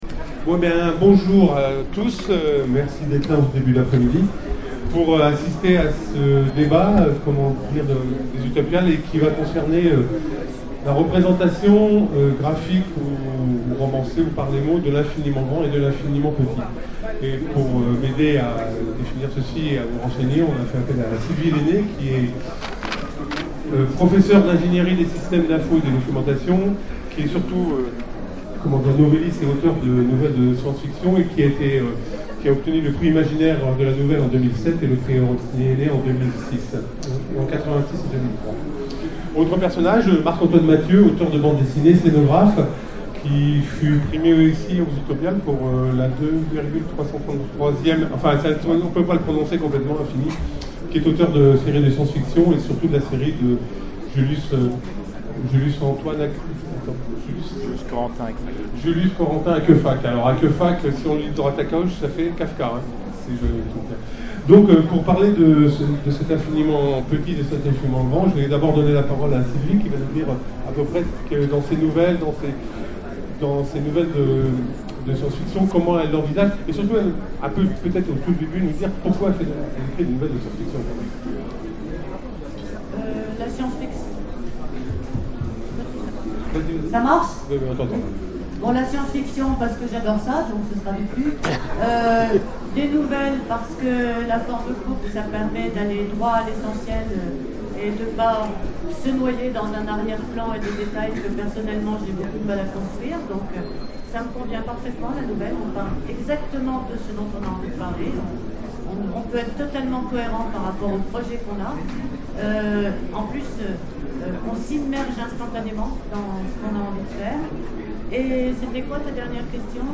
Utopiales 12 : Conférence Comment raconter l’infiniment grand ?